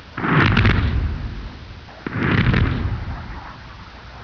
Totally Free War Sound Effects MP3 Downloads
Missleburst.mp3